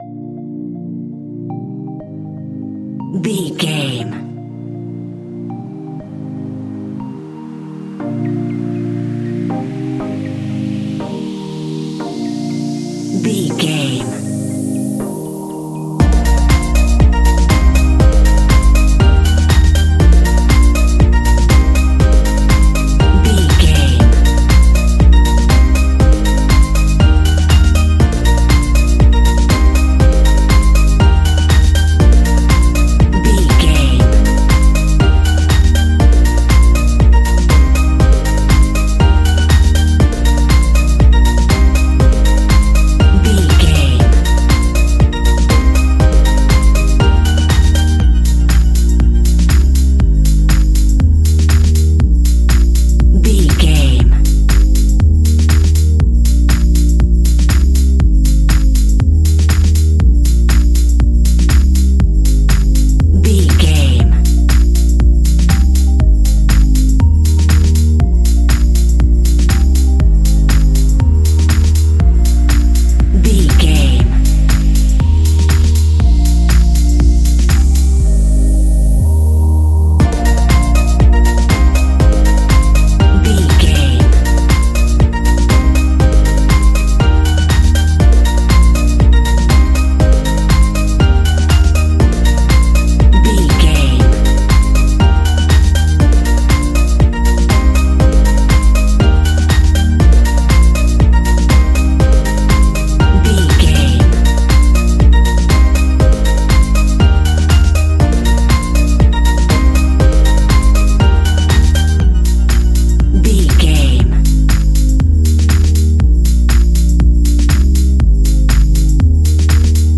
Ionian/Major
D♯
house
electro dance
synths
techno
trance